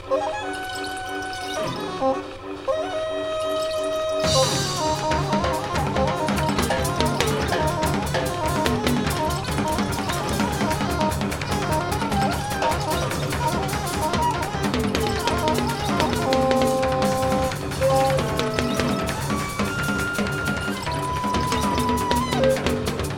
Drums and Percussion
Keyboard, Clarinets and Saxophone.